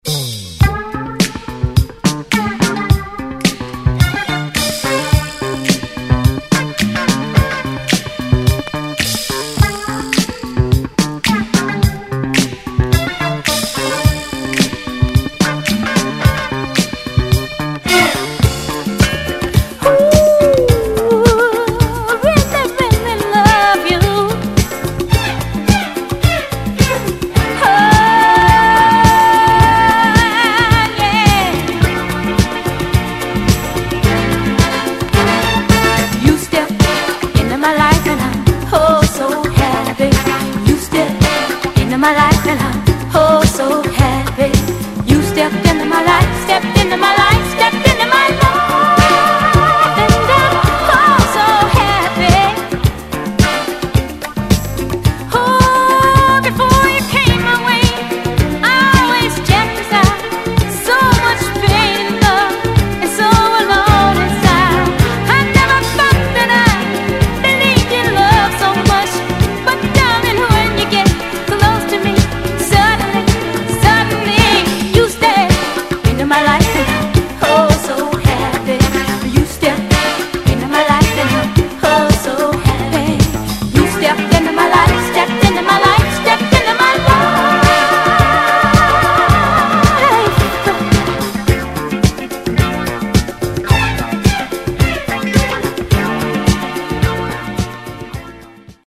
Format: 7 Inch
Disco~Garage Classic！